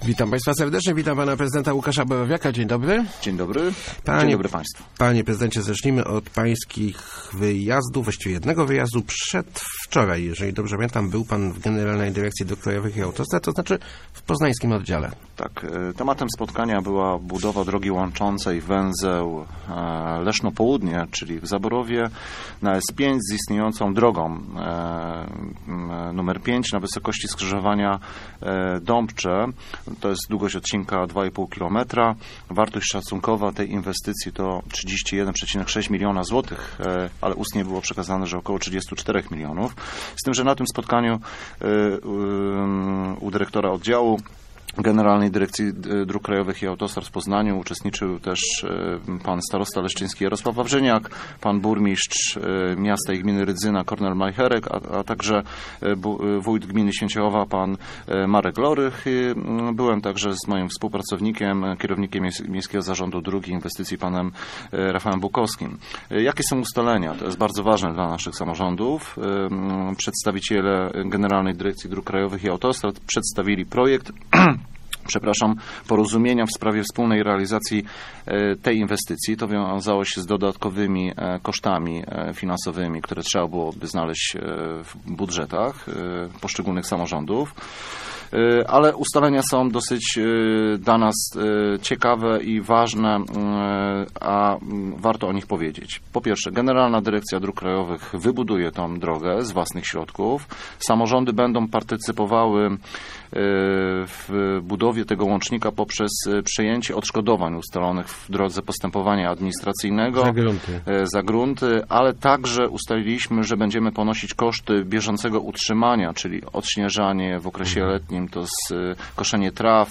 Gościem Kwadransa był prezydent Łukasz Borowiak ...